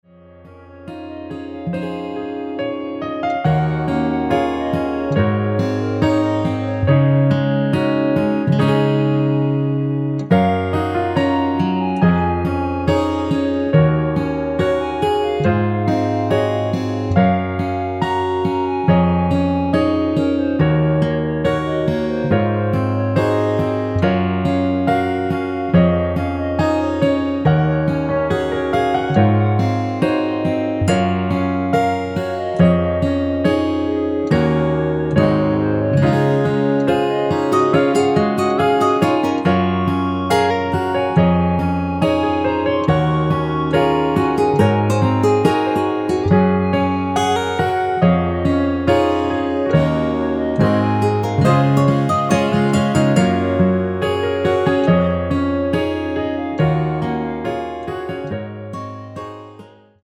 (+3) 멜로디 포함된1절후 후렴으로 진행되게 편곡 하였습니다.(아래의 가사 참조)
Db
◈ 곡명 옆 (-1)은 반음 내림, (+1)은 반음 올림 입니다.
앞부분30초, 뒷부분30초씩 편집해서 올려 드리고 있습니다.